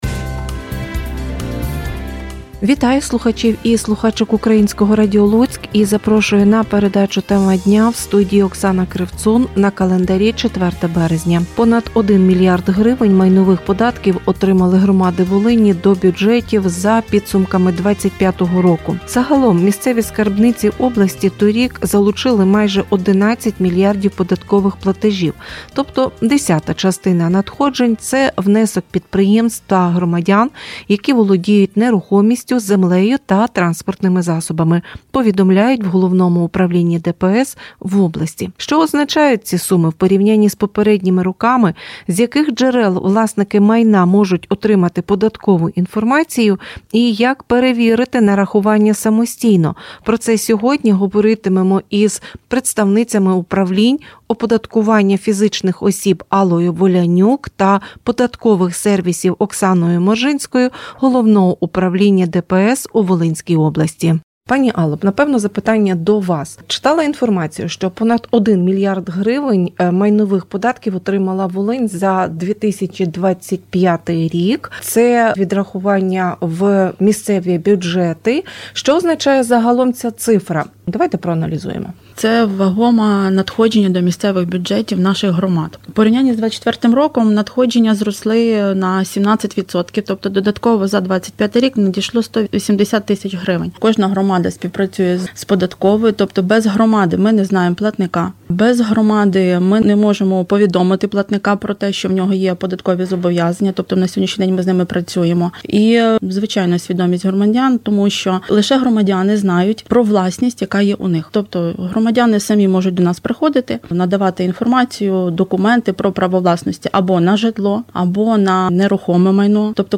Волинські податківці в ефірі Суспільне радіо. Луцьк наголосили власникам майна про важливості своєчасного перерахування податків до місцевих бюджетів та запропонували електронну комунікацію з ДПС.